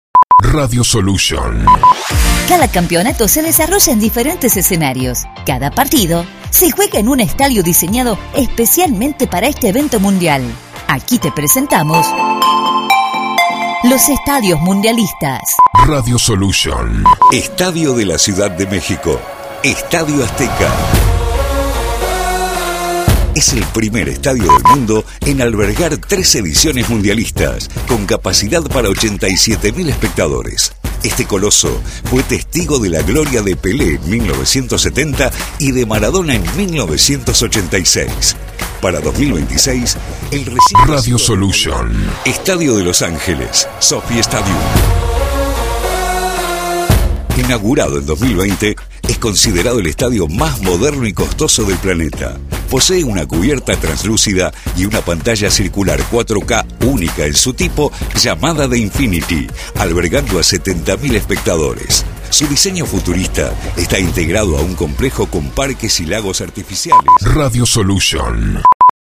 Editados con Música y Efectos
Producción confeccionada a 1 voz